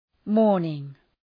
Shkrimi fonetik {‘mɔ:rnıŋ}